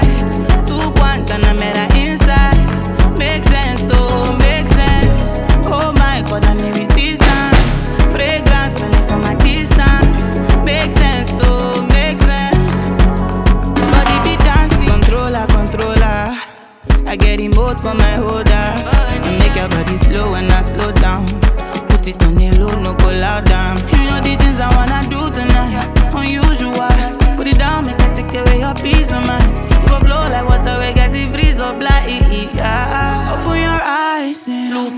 Скачать припев
Afrobeats